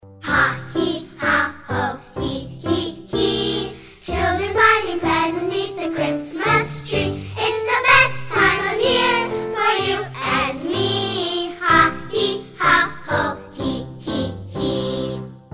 ▪ The full-length music track with vocals.
Listen to a sample of this song.